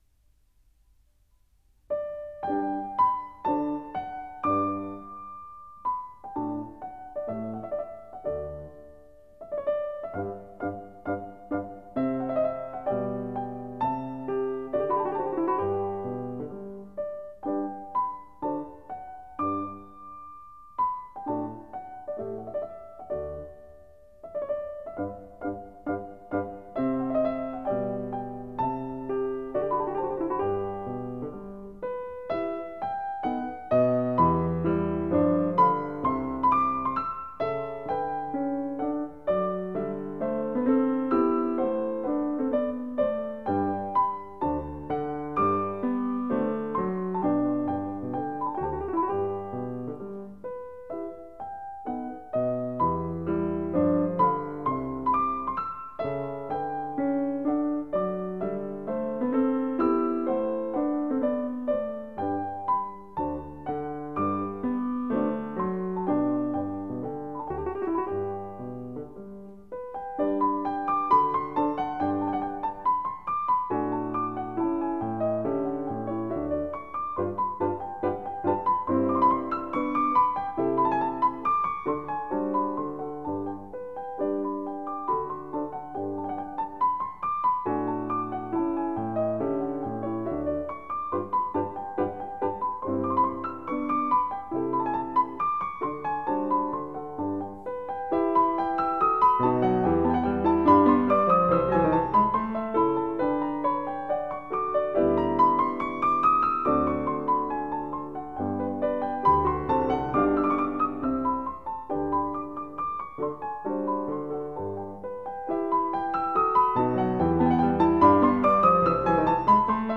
Piano Works/Piano Duets